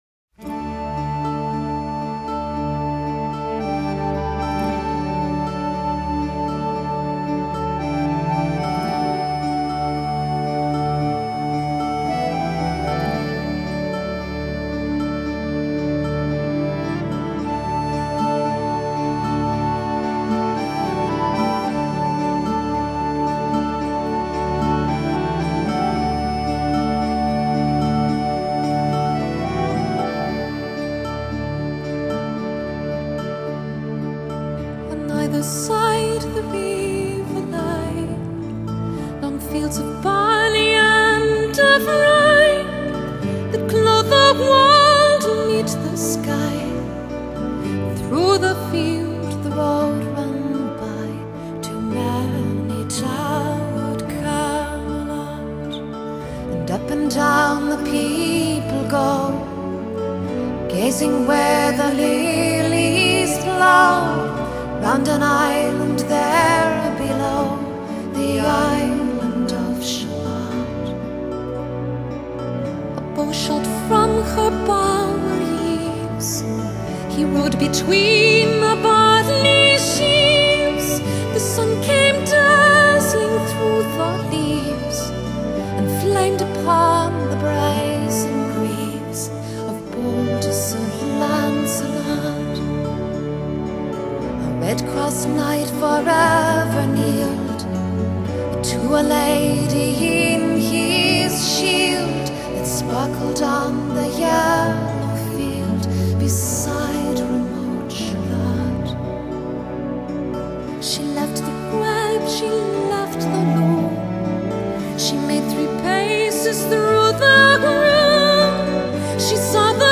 Genre: Celtic, Folk, New Age